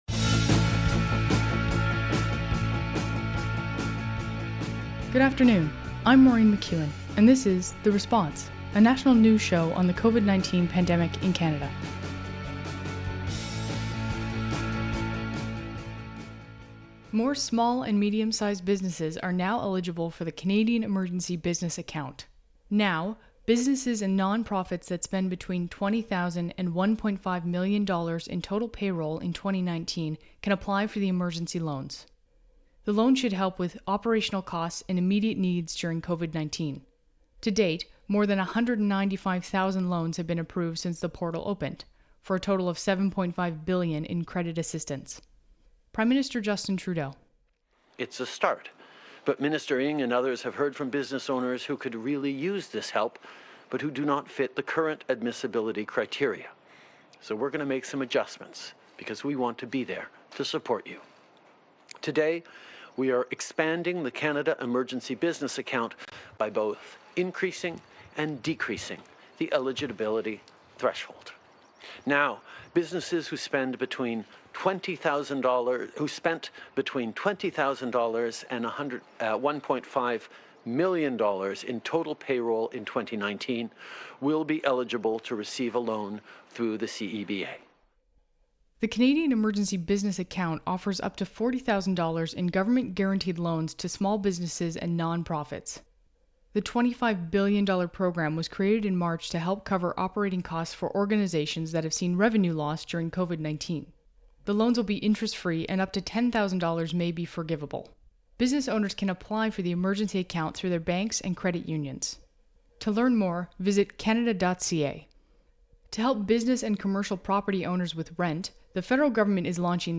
National News Show on COVID-19
Credits: Audio clips: Canadian Public Affairs Channel.
Type: News Reports
192kbps Stereo